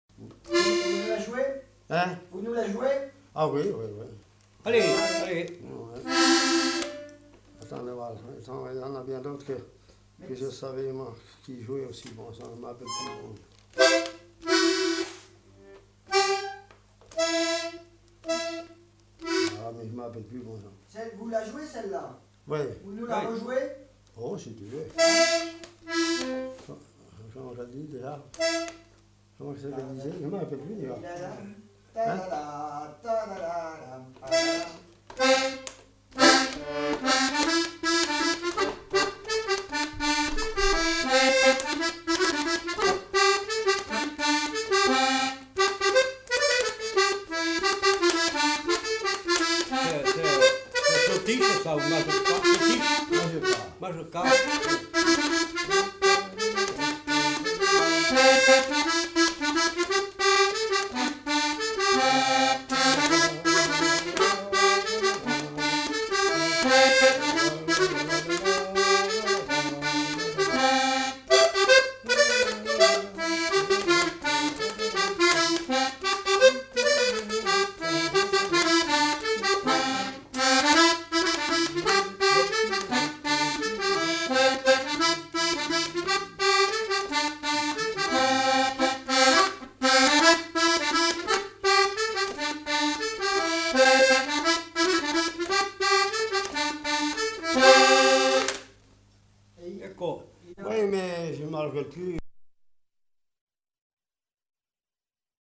Aire culturelle : Auvergne
Lieu : Peschadoires
Genre : morceau instrumental
Instrument de musique : accordéon
Danse : mazurka